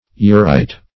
Search Result for " urite" : The Collaborative International Dictionary of English v.0.48: Urite \U"rite\, n. [Gr.